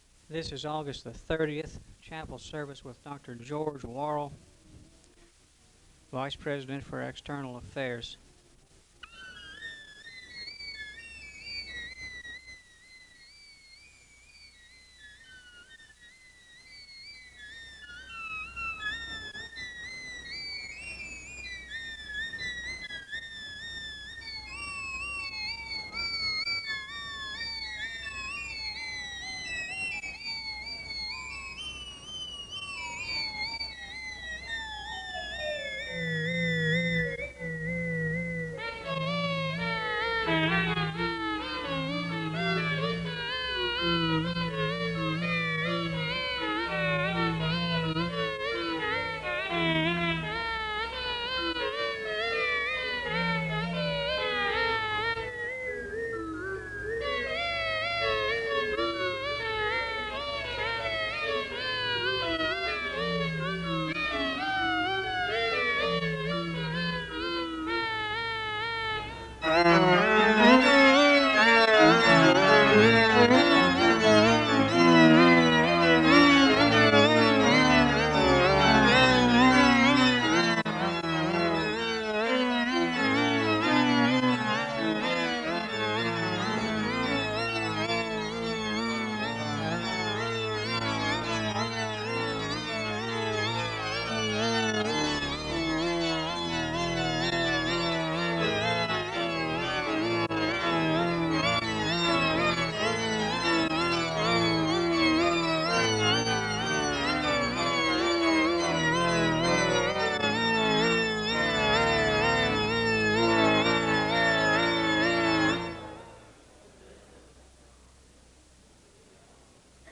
An opening hymn is played (0:08-4:31).
The choir sings a song of worship (9:50-13:41).